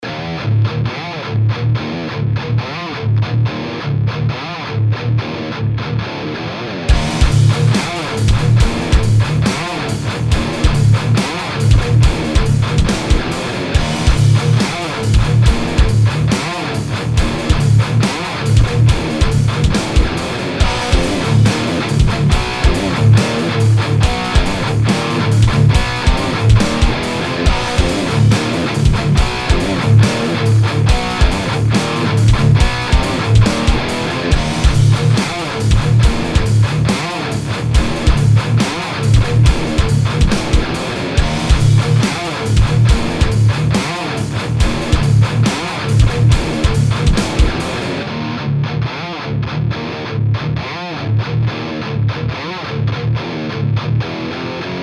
Pour les basses, pas de problème, yen a plein et ça passe bien. Il faut juste noter que tu auras sensiblement moins d'aigus que sur du céramique...
VTM-TestEpiphoneMetal_PodXT.mp3